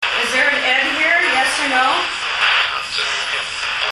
Port Gamble EVP/AEP's  October 2009
The following are some of the best EVP/EAP's that we received during our investigation of the New York House "Old Hospital", Walker Ames House and the Community Center.